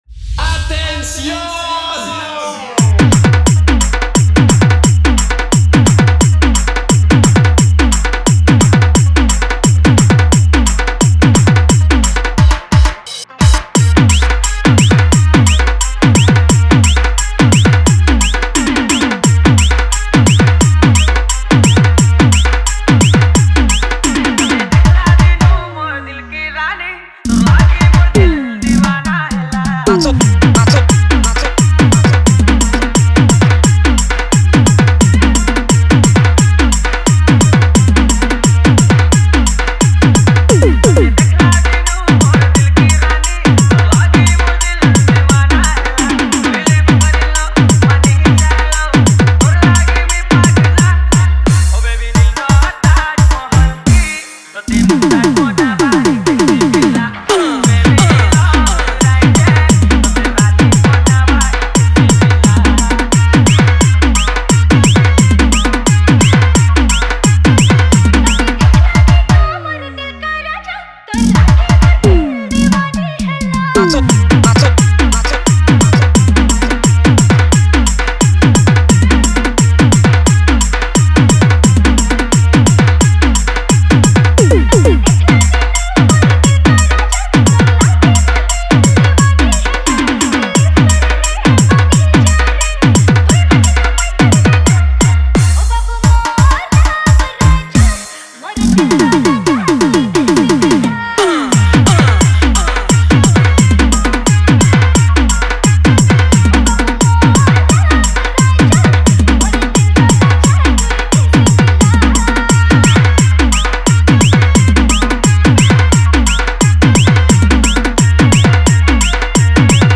Category:  Sambalpuri Dj Song 2021